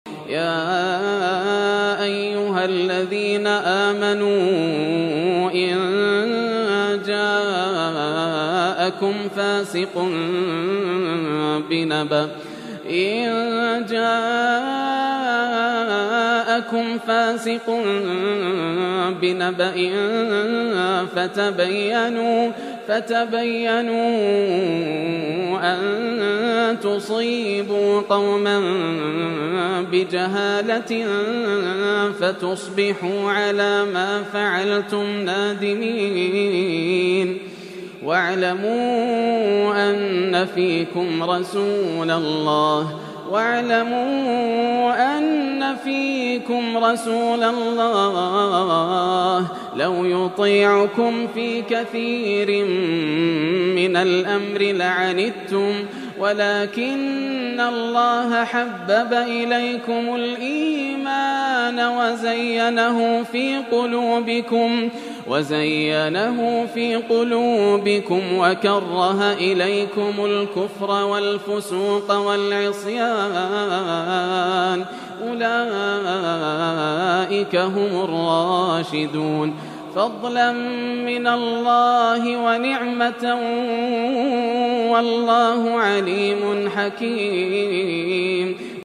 سُورة الحجرات أندر وأجمل تلاوة قد تسمعها للشيخ ياسر الدوسري > مقتطفات من روائع التلاوات > مزامير الفرقان > المزيد - تلاوات الحرمين